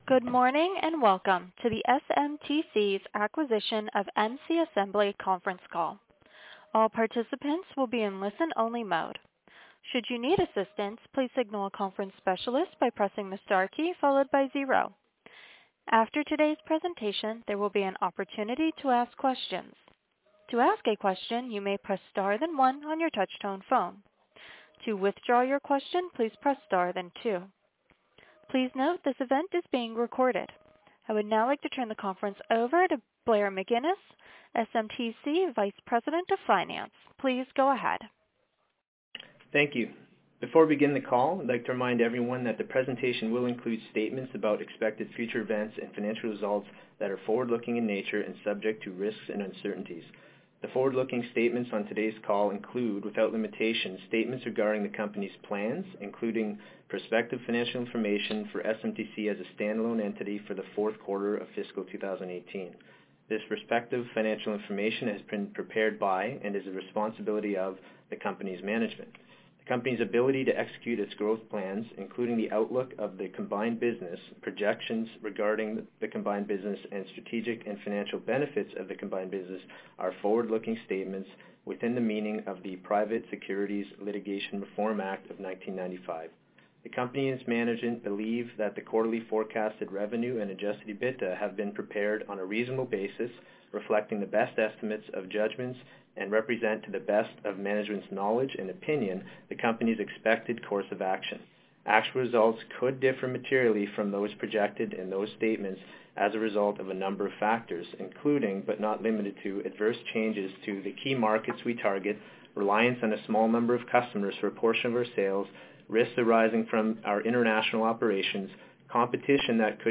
Acquisition Conference Call